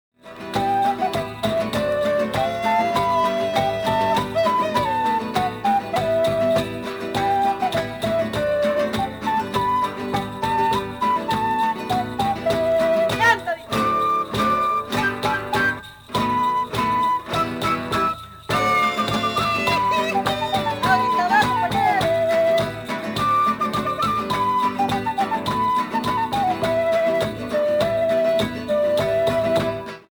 played by Atacama of Chile